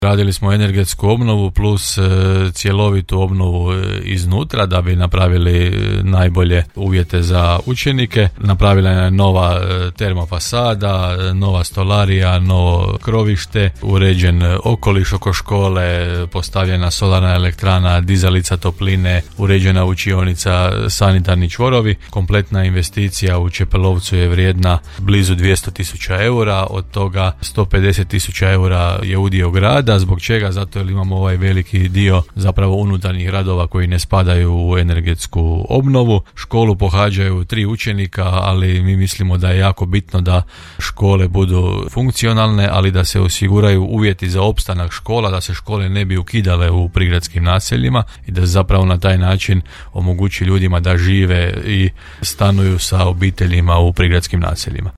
Poseban razlog za veselje imaju upravo učenici Čepelovca jer su u klupe sjeli u potpuno obnovljenoj školskoj zgradi, što je ranije za Podravski radio komentirao gradonačelnik Janči;
-kazao je Janči. Riječ je o nastavku Zelenih projekata kojima Grad Đurđevac sustavno unaprjeđuje obrazovne ustanove i ulaže u održivu budućnost.